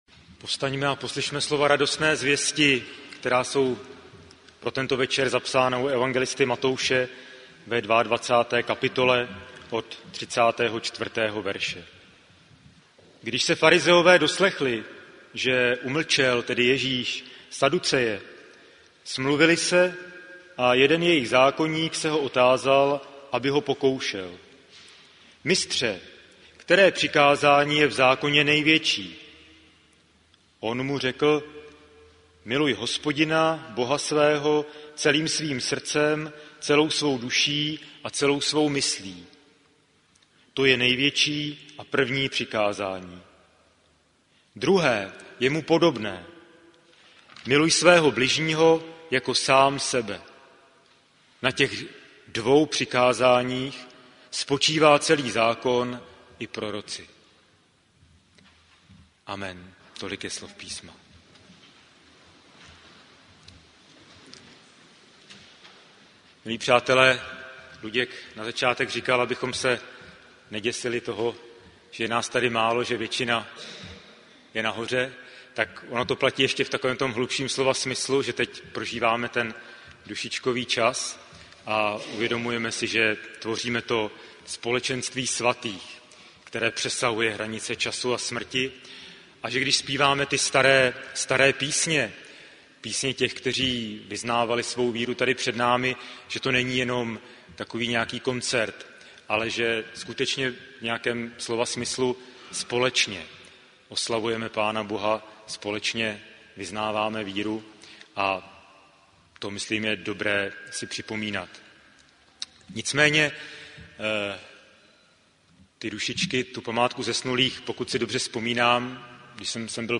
Tradiční hudební nešpory pořádané v našem sboru od roku 1961.
Jako již tradičně v podvečer první listopadové neděle pořádal náš sbor hudební nešpory. V programu nešpor zazněly chorály a chorální předehry z počátků světové reformace.